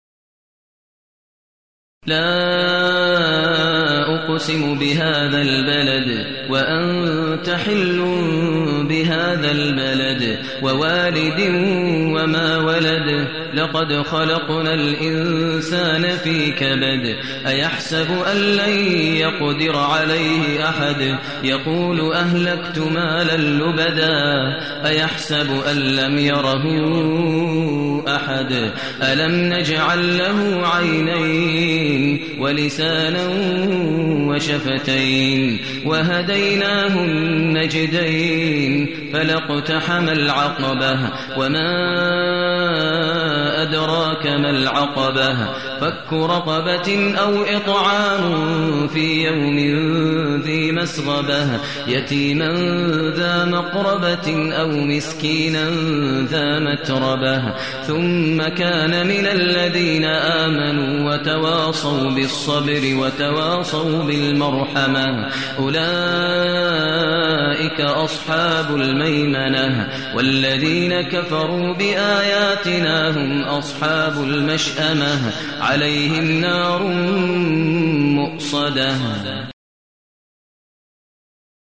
المكان: المسجد النبوي الشيخ: فضيلة الشيخ ماهر المعيقلي فضيلة الشيخ ماهر المعيقلي البلد